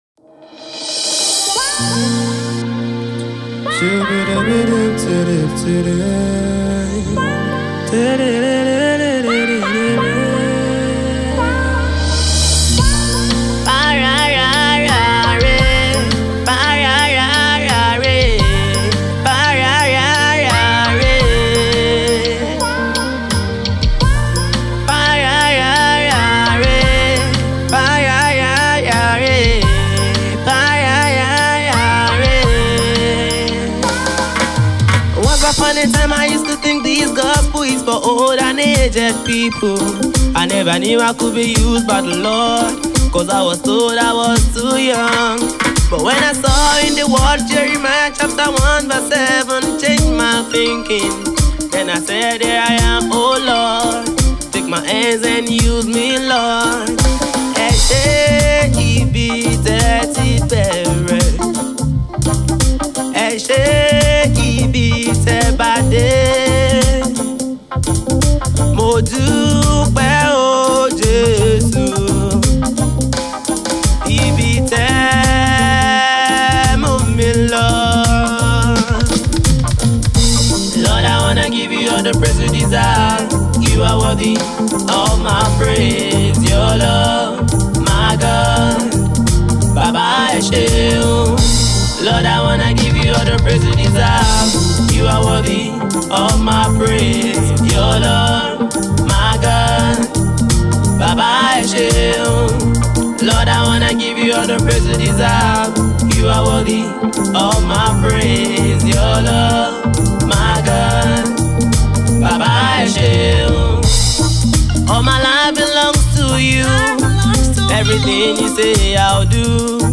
finely blends indigenous element with contemporary sounds
with Reggae beat